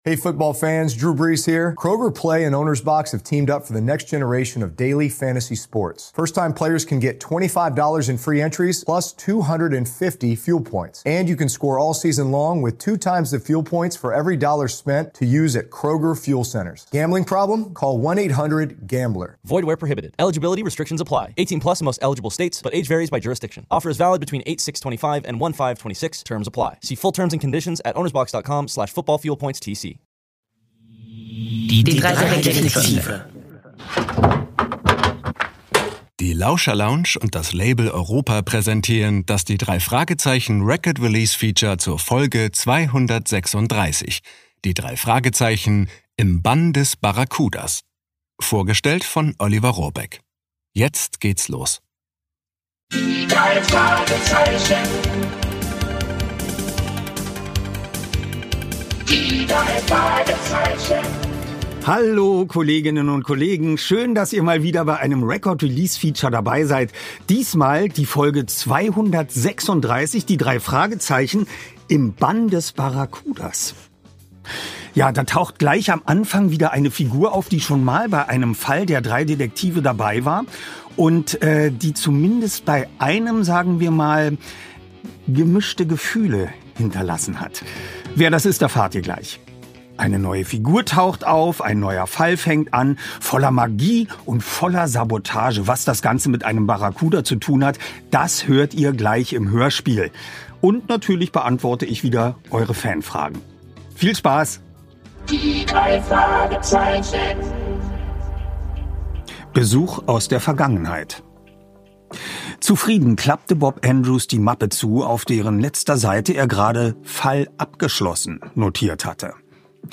Sprecher: Oliver Rohrbeck